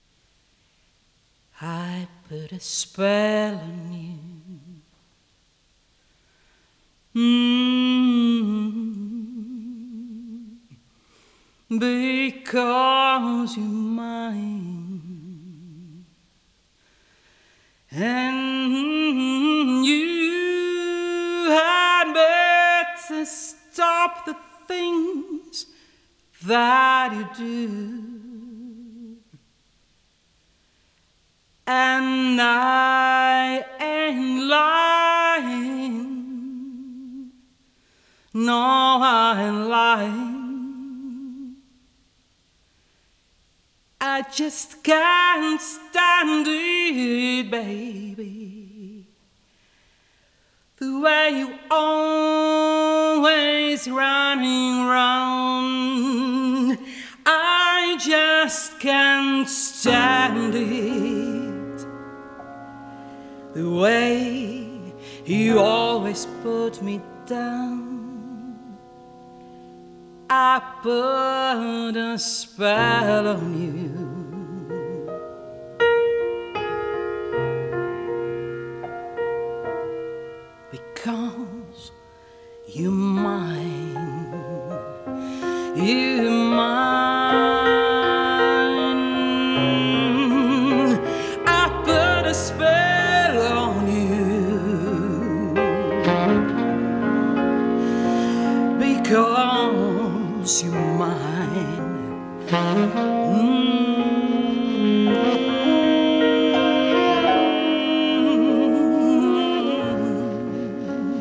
The recording has not been edited
Live DSD 256 recording
Jazz Standards
vocal and piano
double bass
saxaphones
drums